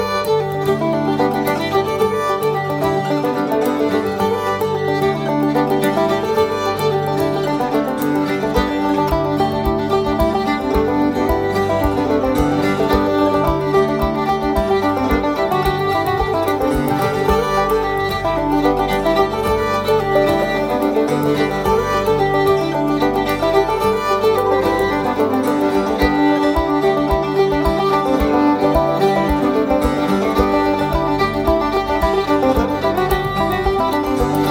Bodrhan
Double bass
Irish traditional fiddle & 5 string banjo